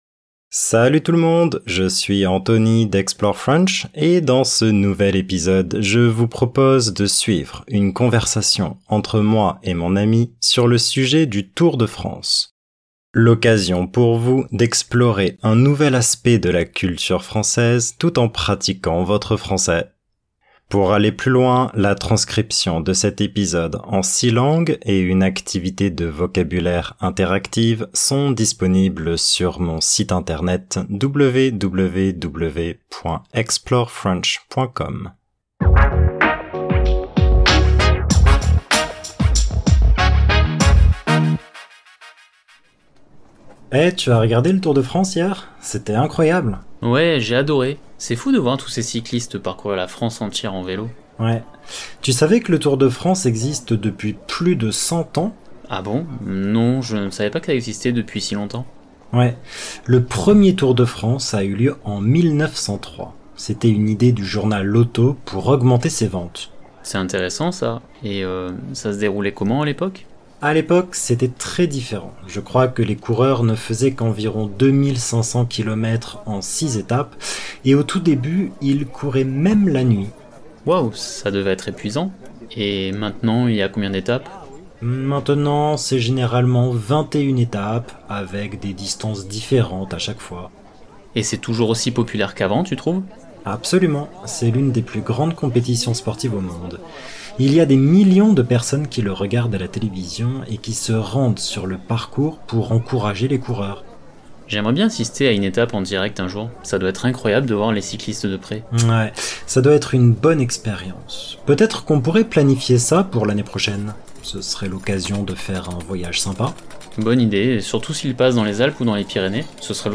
and have no regional accent.